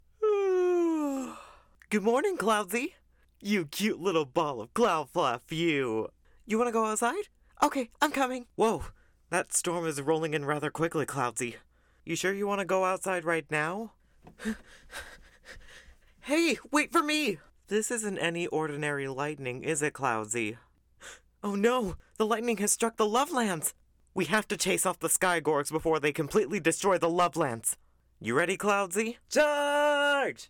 Sky Gorgs Are the Enemy - Animated, Genuine
North American (General), North American (US New York, New Jersey, Bronx, Brooklyn), North American (US South), North American (Mid-Atlantic), British (England - East Midlands)